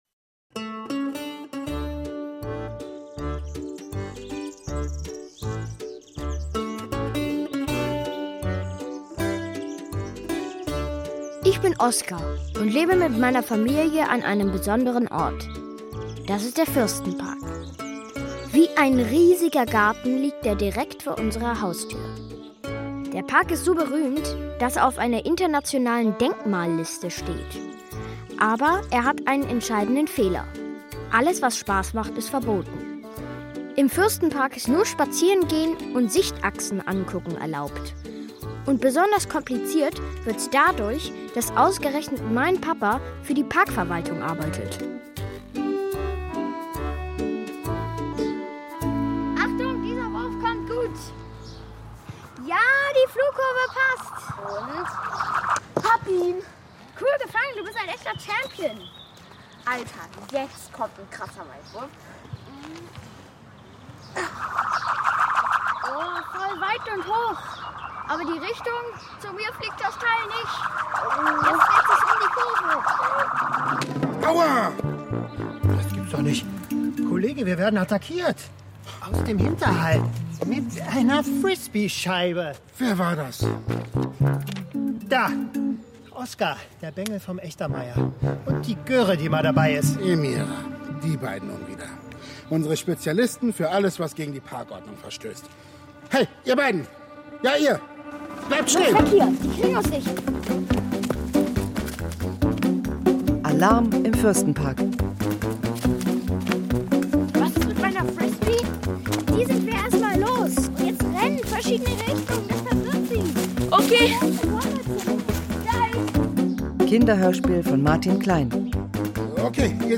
Kinderhörspiel - Alarm im Fürstenpark